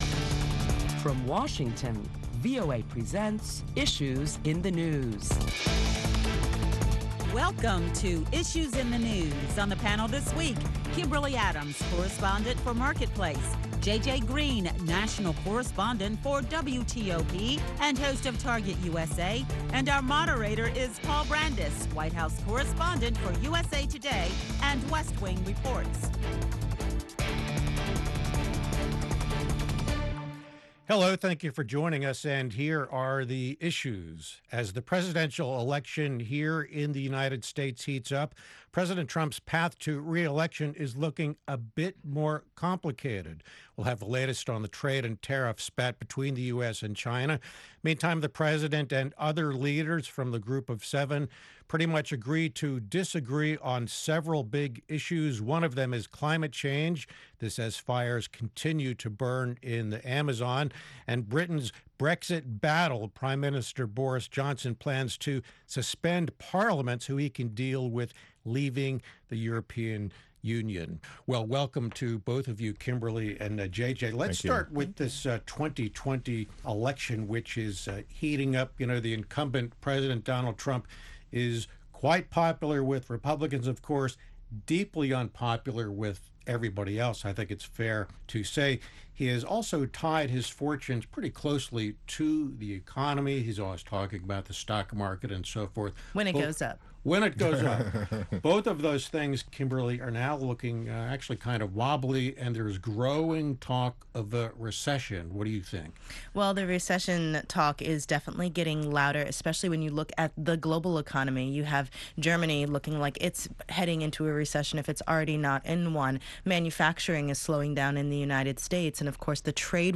Listen to a panel of prominent Washington journalists as they deliberate the latest top stories, which include U.S. President Trump’s path to a 2020 re-election… and how world leaders commit to fighting the wildfires in the Amazon…